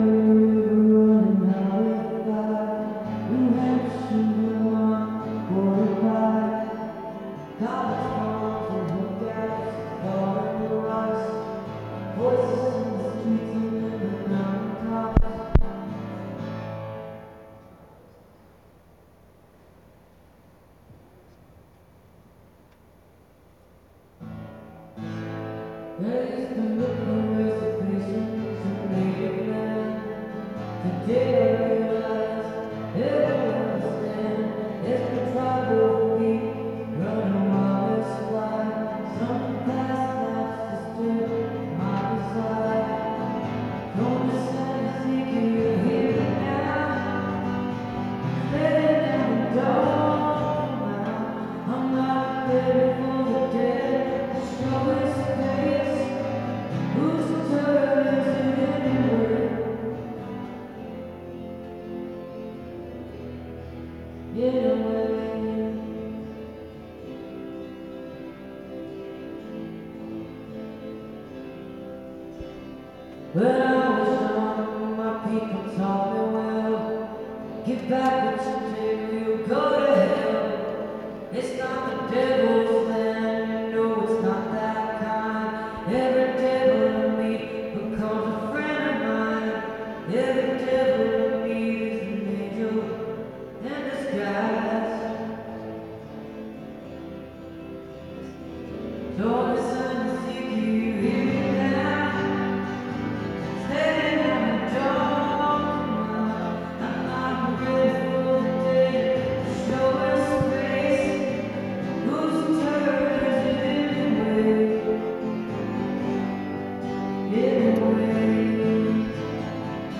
acoustic duo
(soundcheck)